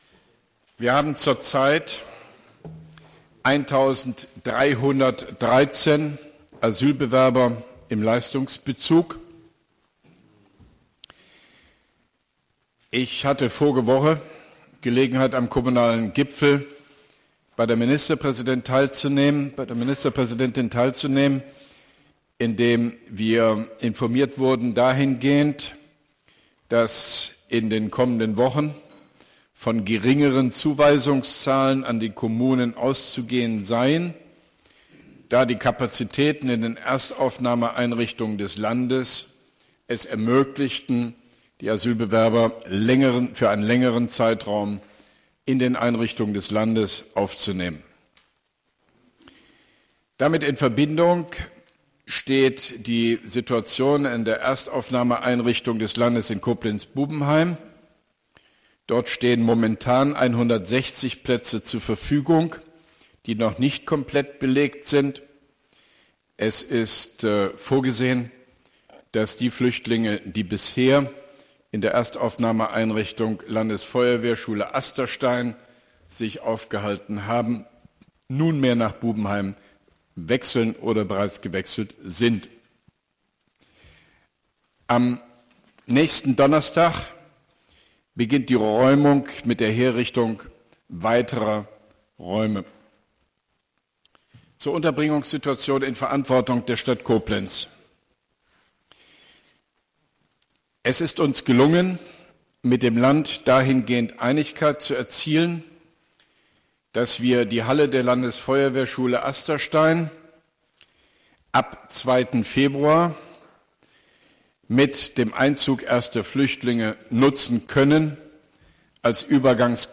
Auszug: Bericht zur Koblenzer Flüchtlingssituation von OB Hofmann-Göttig vor dem Koblenzer Stadtrat, 28.01.2016